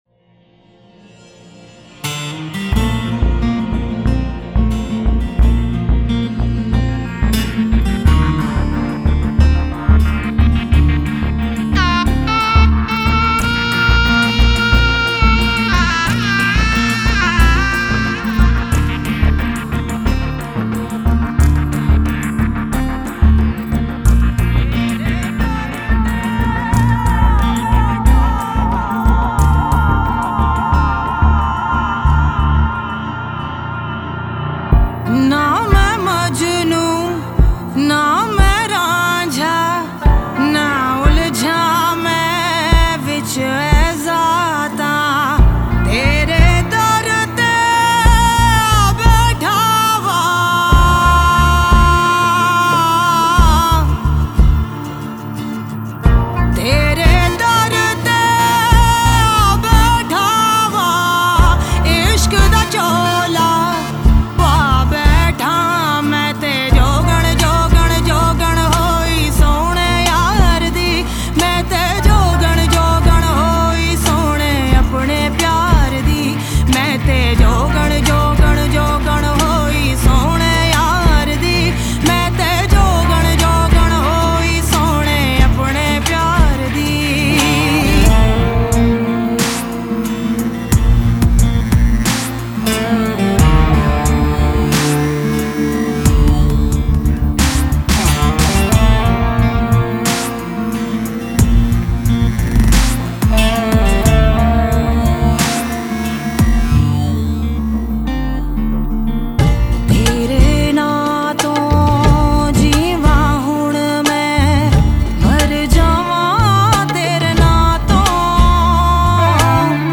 Kalaam/Poetry  ﭙﻨﺠﺎﺑﻰ punjabi
Sufi rock songs
distinct and heavy voice suits the composition well